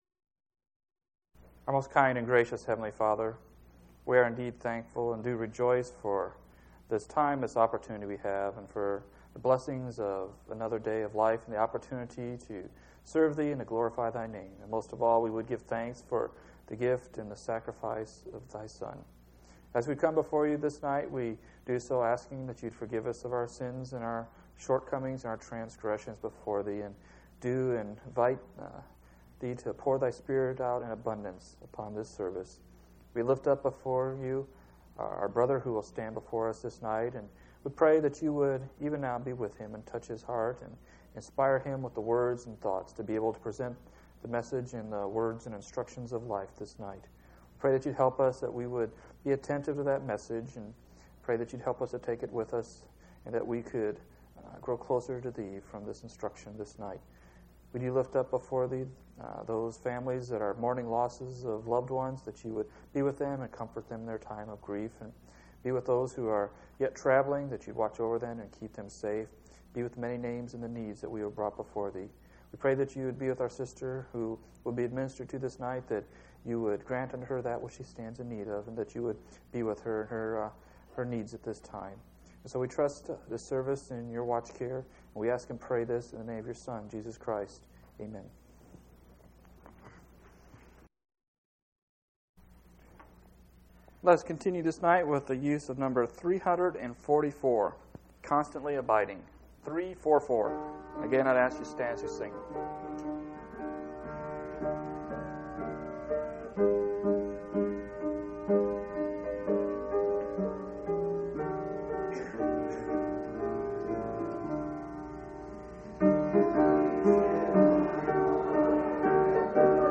8/13/2000 Location: Phoenix Local Event